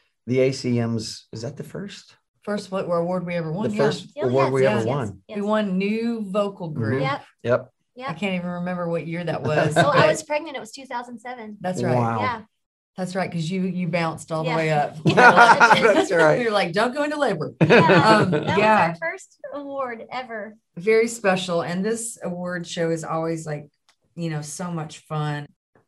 The members of Little Big Town talk about winning their very first award at the ACMs.